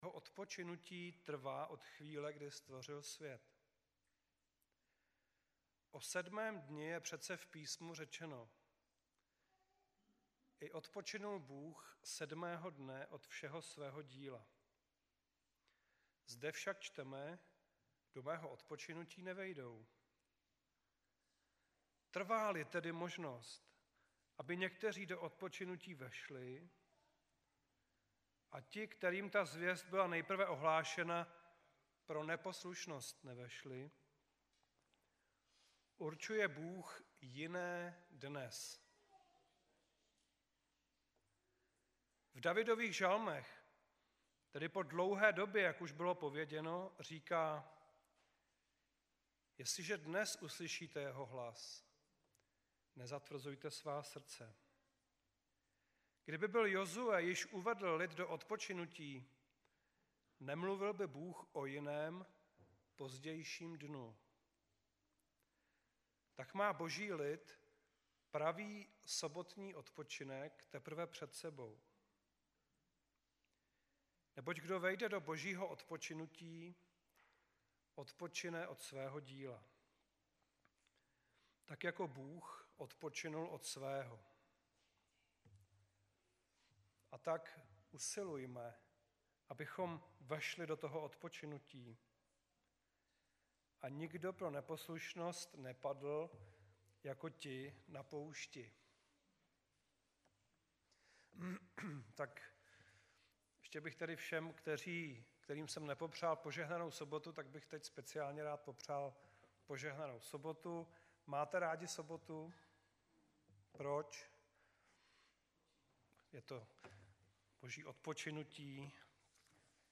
25.3.2017 v 21:27 do rubriky Kázání .